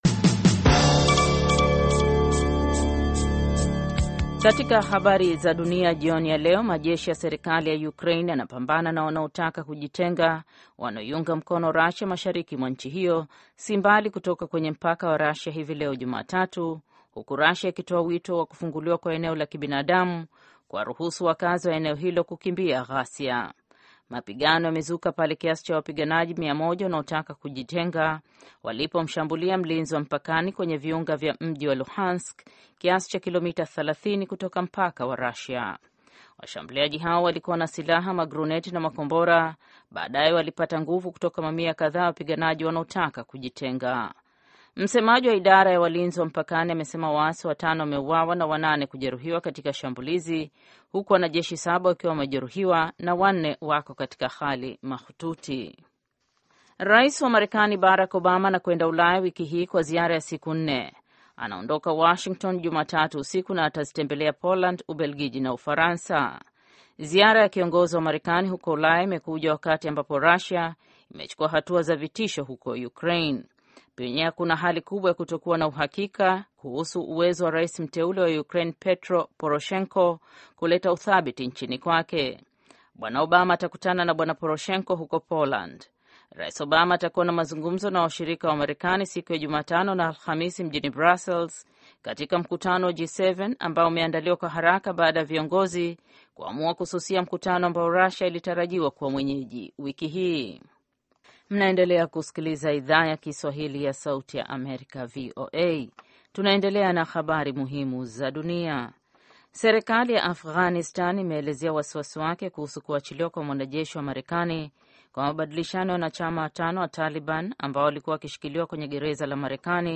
Taarifa ya Habari VOA Swahili - 4:20